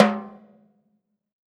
BTIMBALE H1J.wav